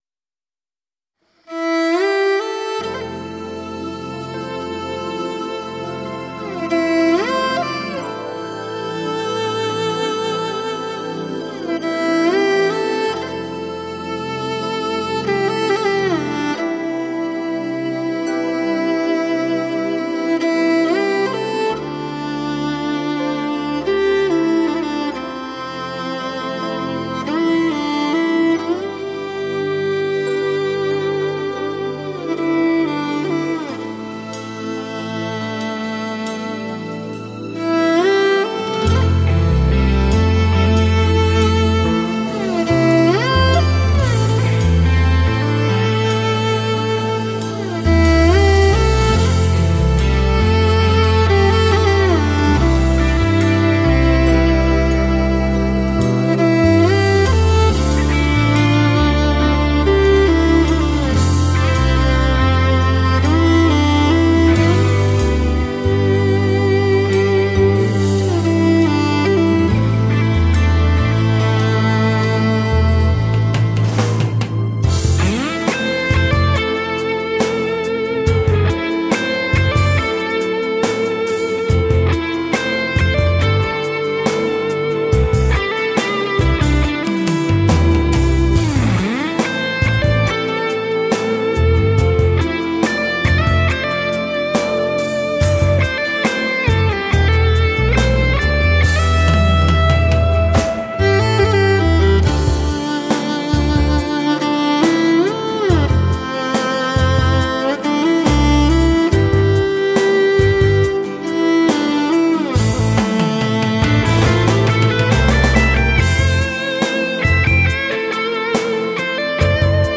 中国民族器乐马头琴发烧天碟，原生态的味道，后现代的色彩。